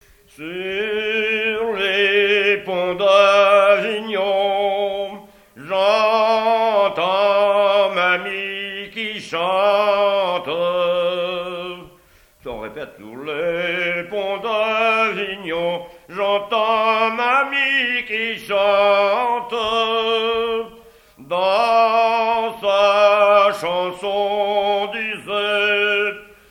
Genre laisse
Mission Ile-d'Yeu
Pièce musicale inédite